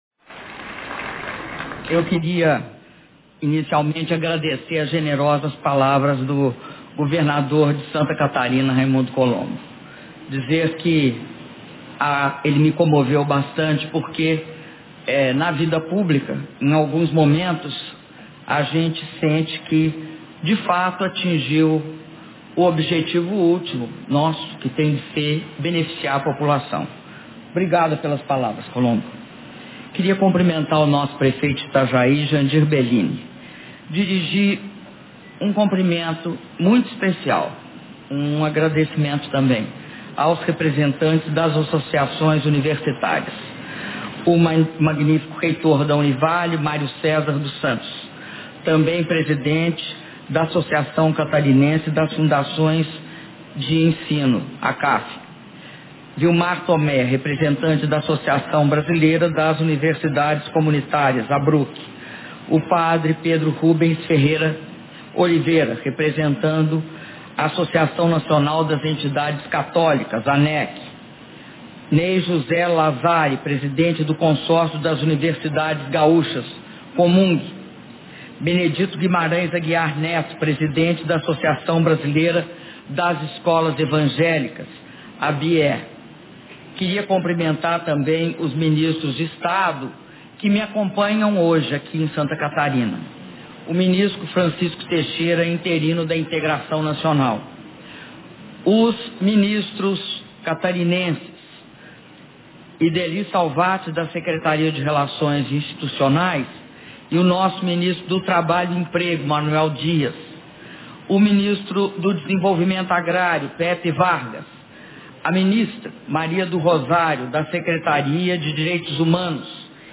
Áudio do discurso da Presidenta da República, Dilma Rousseff, na cerimônia de assinatura da ordem de serviço das obras de ampliação das Barragens de Taió e Ituporanga - Itajaí/SC